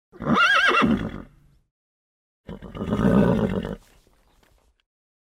На этой странице собрана коллекция натуральных звуков ржания лошадей.
Звук конского ржания